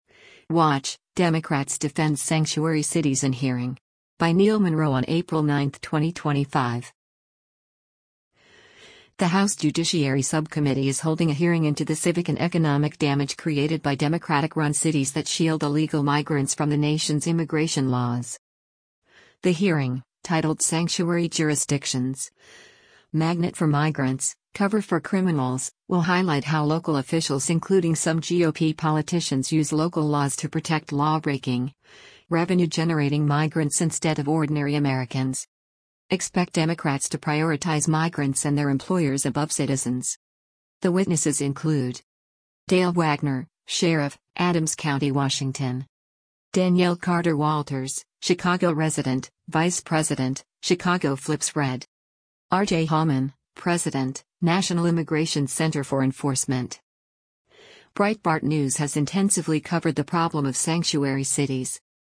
The House Judiciary Subcommittee is holding a hearing into the civic and economic damage created by Democratic-run cities that shield illegal migrants from the nation’s immigration laws.
Dale Wagner, Sheriff, Adams County, Washington